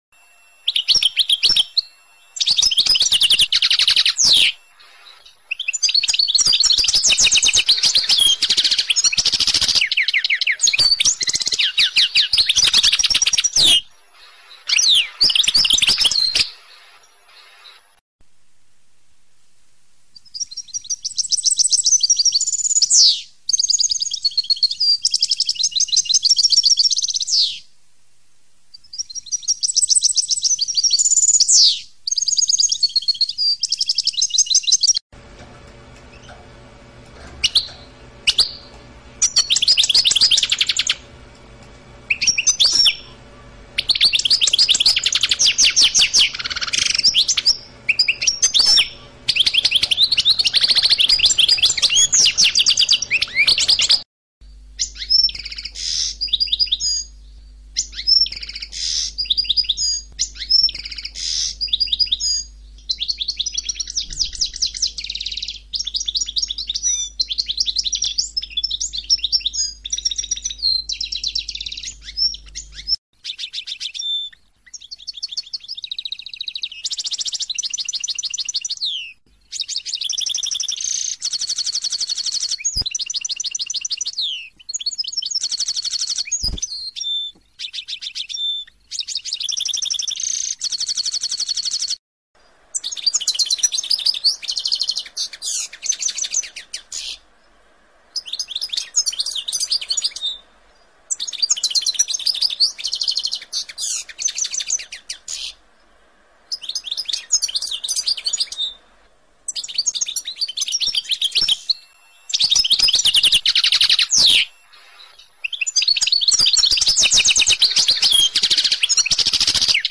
Chant-Oiseaux.mp3